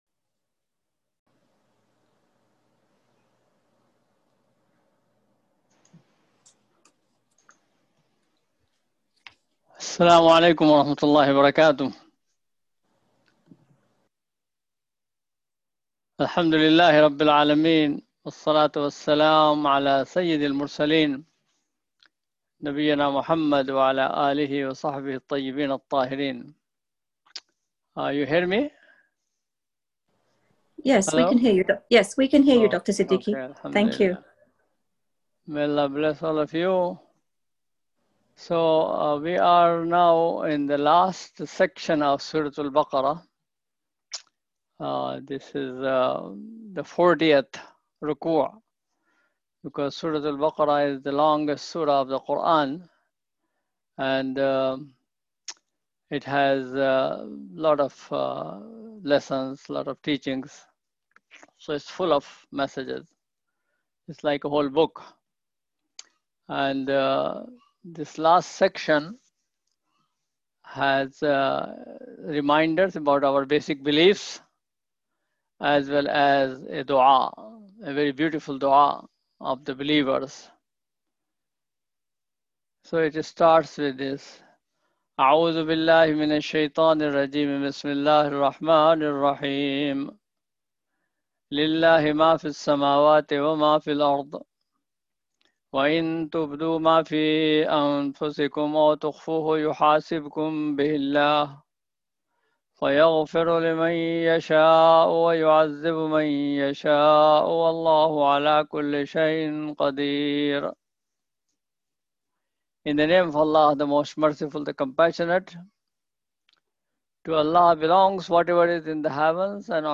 Weekly Tafseer